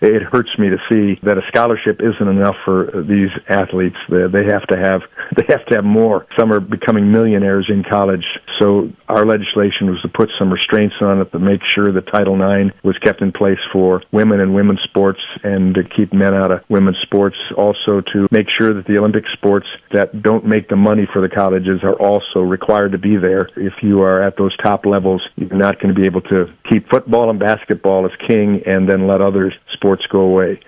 US Congressman Tim Walberg regularly joins A.M. Jackson Friday mornings.